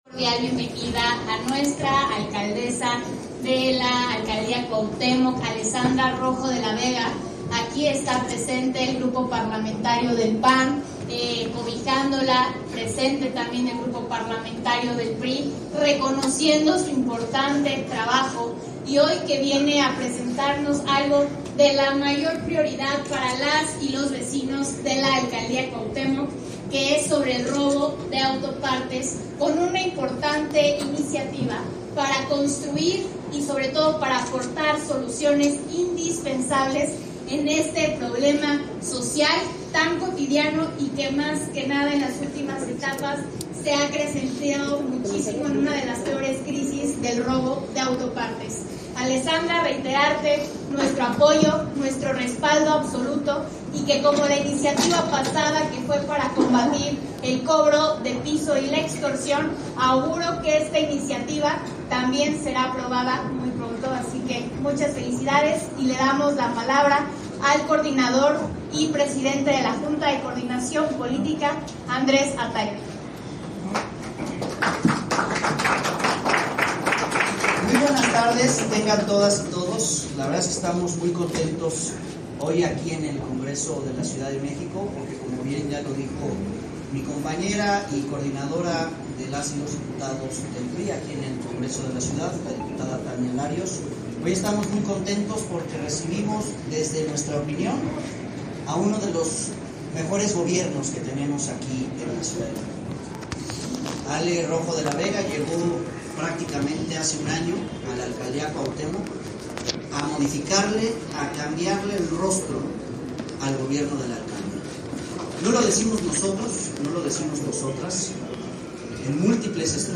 En conferencia de prensa llevada a cabo en el Recinto Legislativo de Donceles, Rojo de la Vega recordó que esta propuesta surge del contacto permanente con la ciudadanía.